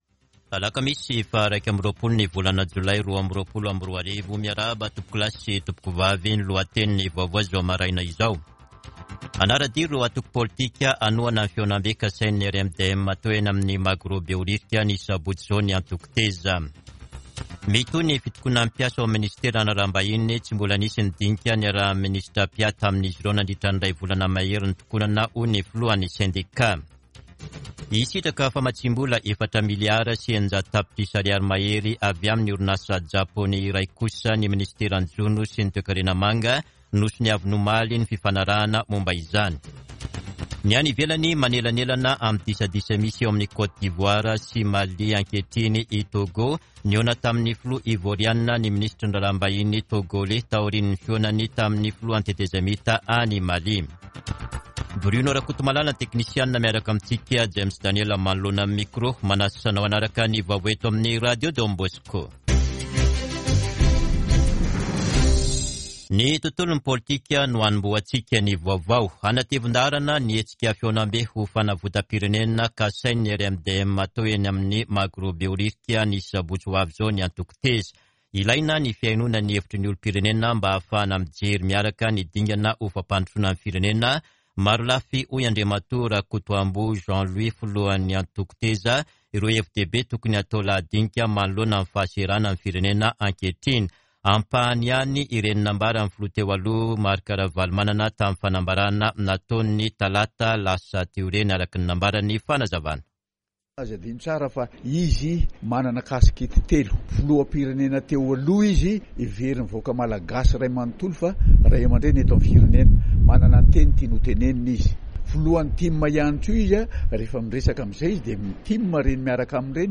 [Vaovao maraina] Alakamisy 21 jolay 2022